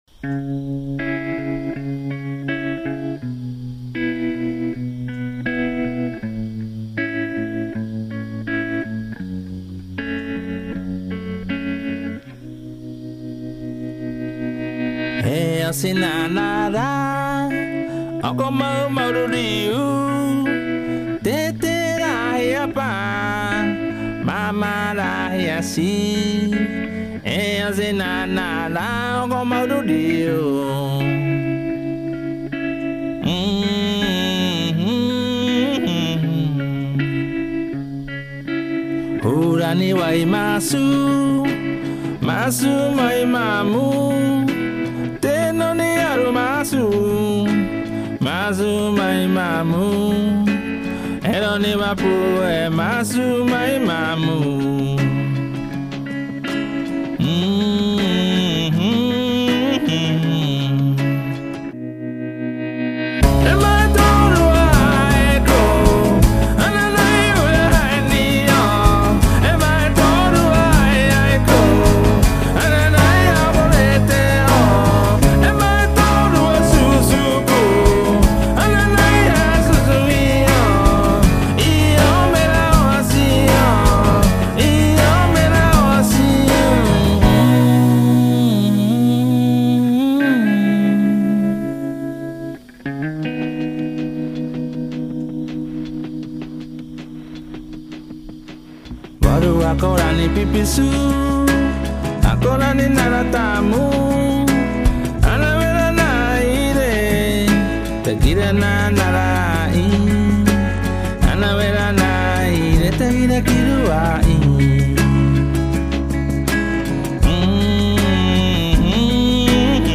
地区: 美国
他也知道如何去到那些偏远的部落里发掘纯粹的没有被搀杂其他因素的古老音乐。